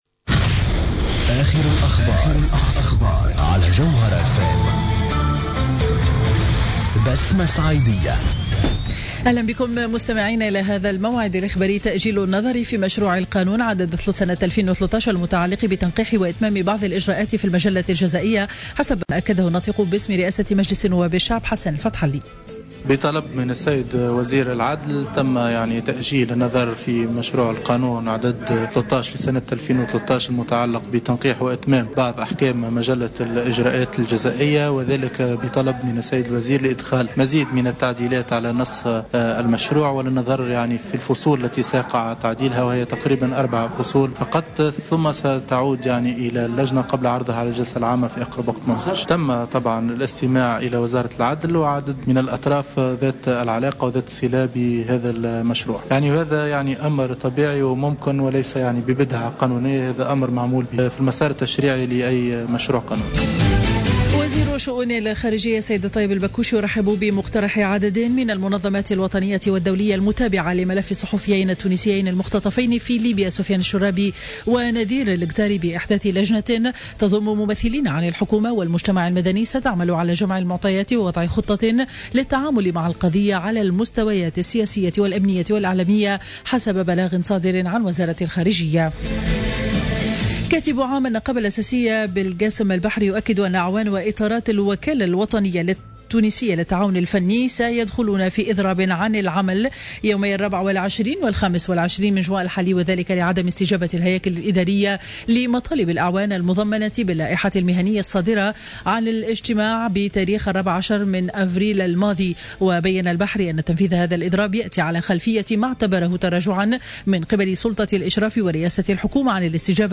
نشرة أخبار منتصف النهار ليوم الثلاثاء 23 جوان 2015